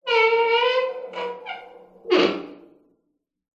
Звуки дверей
Звук скрипучей старой двери, подземный гул, металлический скрежет, мрачная атмосфера